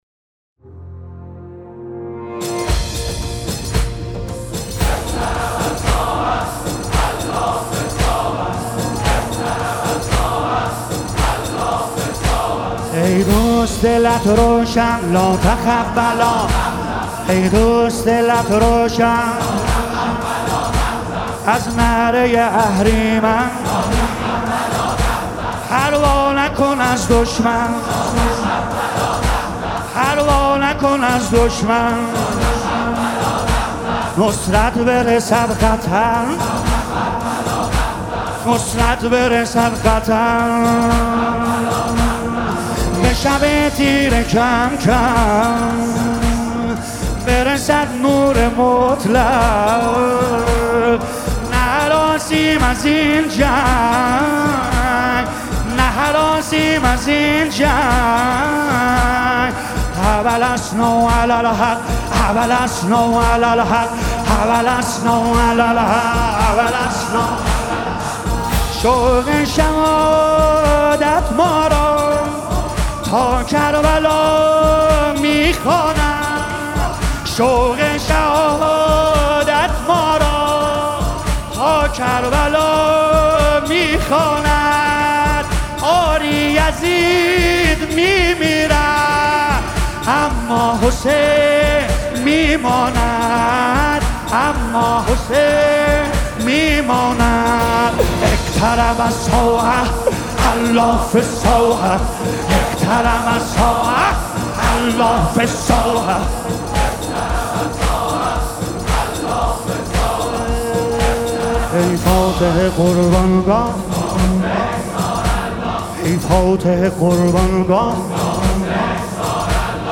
مداحی حماسی